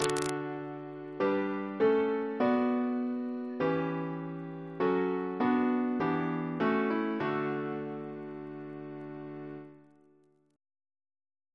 Single chant in D Composer: Donald B. Eperson (1904-2001) Reference psalters: ACB: 156